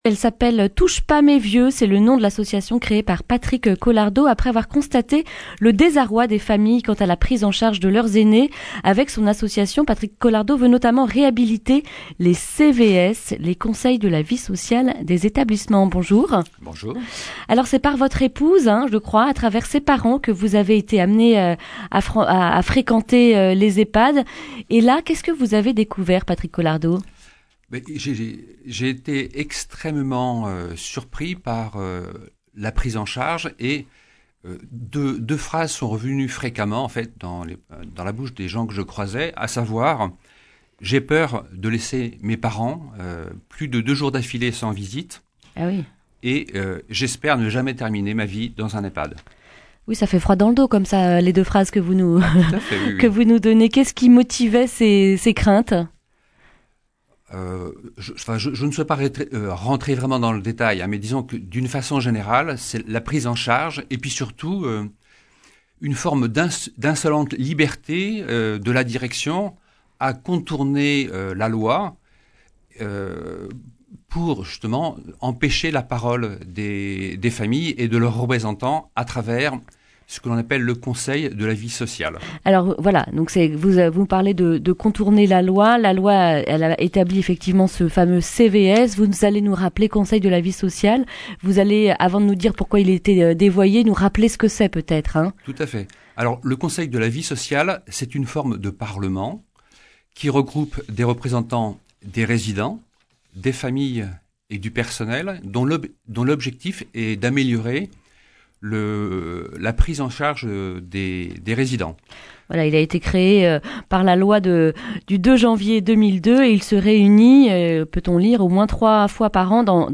lundi 25 novembre 2019 Le grand entretien Durée 10 min